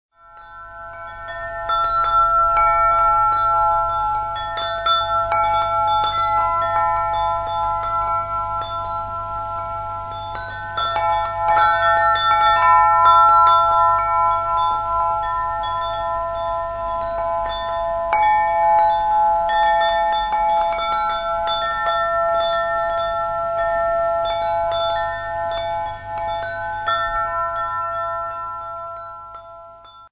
Klangspiel-Komposition: " Kinderseele " Planetenton: Sonne Ein Willkommen für die Babys in dieser Welt, sowie für das "kleine Kind" in uns.
Planetenton: Sonne Ein Willkommen für die Babys in dieser Welt, sowie für das „kleine Kind“ in uns.